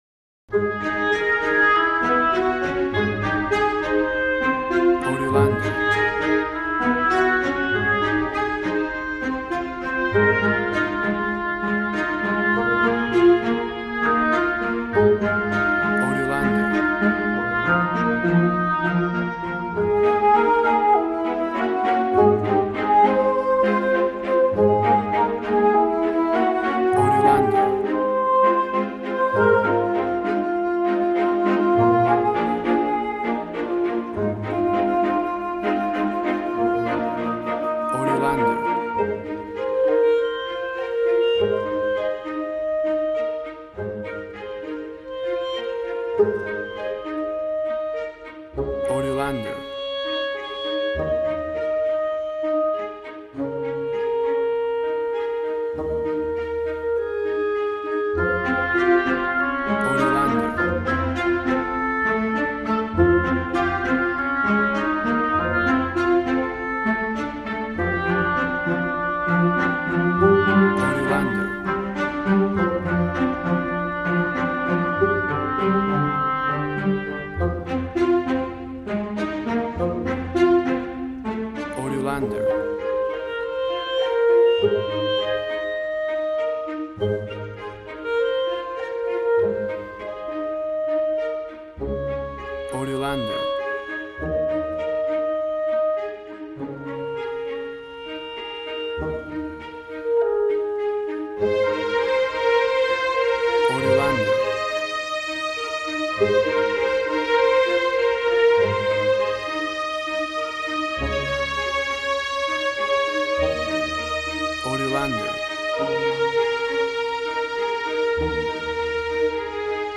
Tempo (BPM): 100